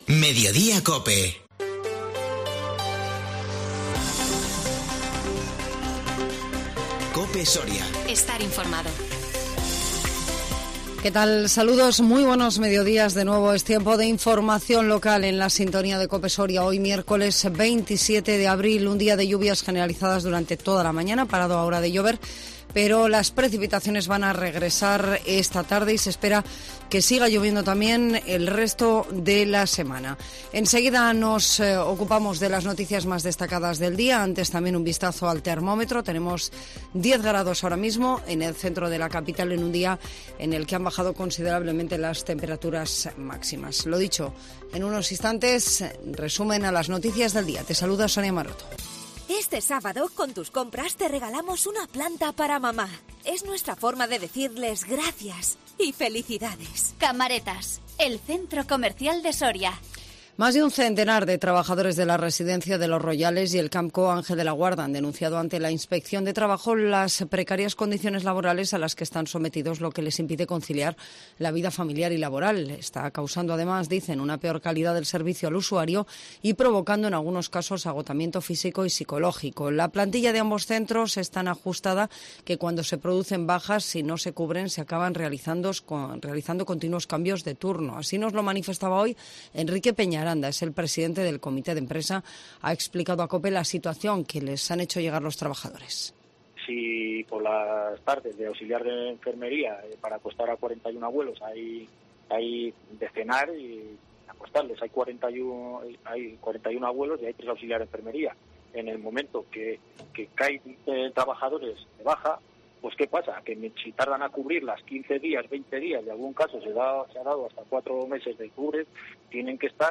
INFORMATIVO MEDIODÍA COPE SORIA 27 ABRIL 2022